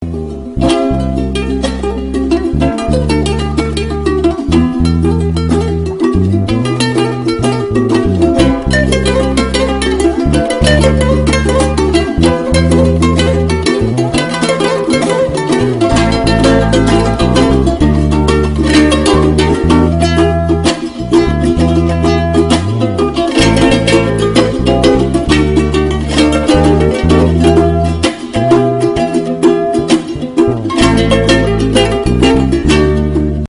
Anime Theme Ringtone